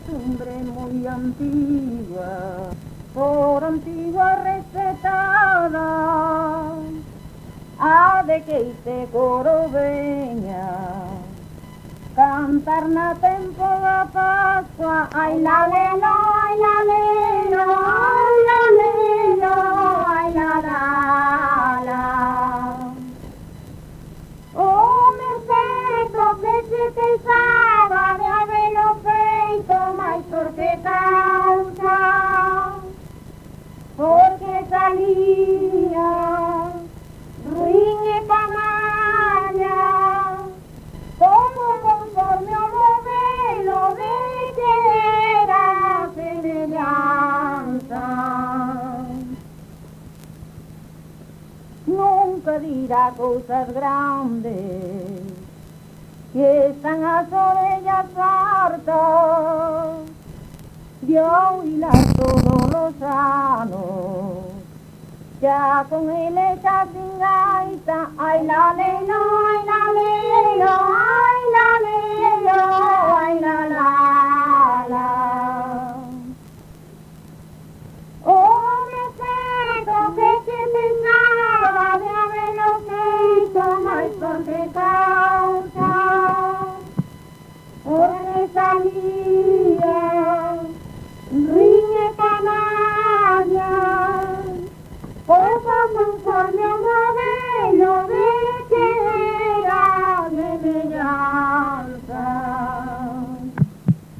Concello: Chantada.
Tipo de rexistro: Musical
Áreas de coñecemento: LITERATURA E DITOS POPULARES > Cantos narrativos
Soporte orixinal: Casete
Instrumentación: Voz
Instrumentos: Voz feminina